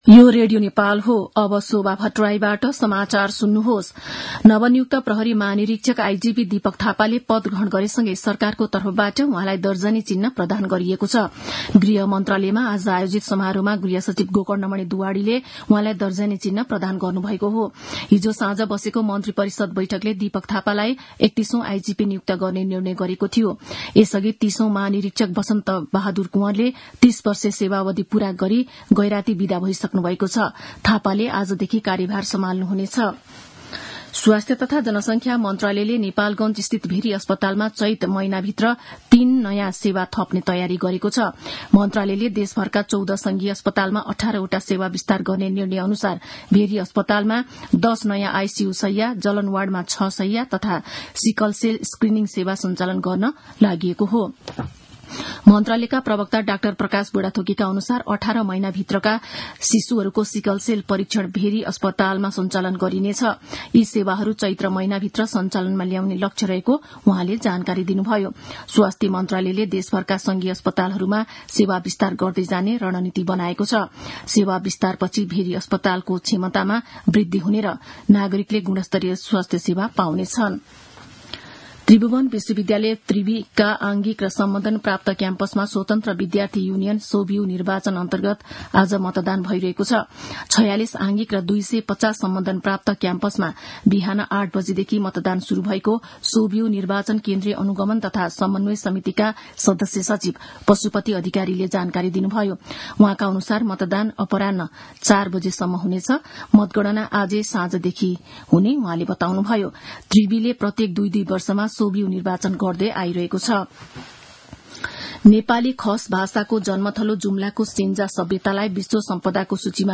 मध्यान्ह १२ बजेको नेपाली समाचार : ५ चैत , २०८१